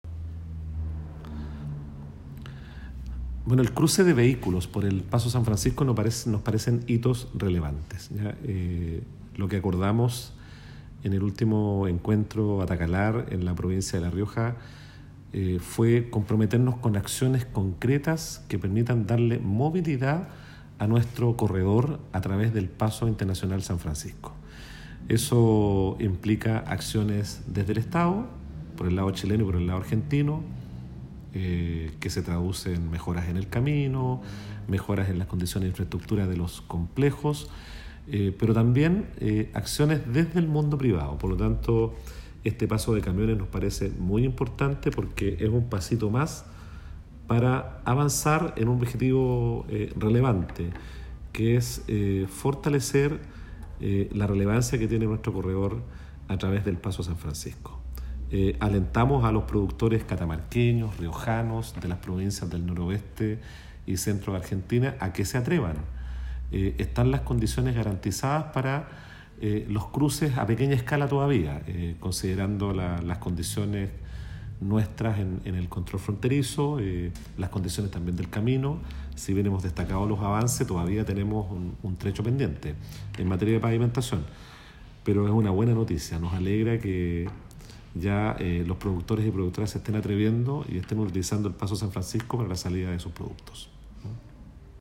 Audio Gobernador Regional de Atacama
Gobernador-Miguel-Vargas-Correa1.m4a